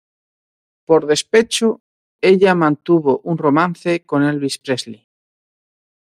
Read more a male given name from English Frequency C1 Hyphenated as El‧vis Pronounced as (IPA) /ˈelbis/ Etymology Unadapted borrowing from English Elvis.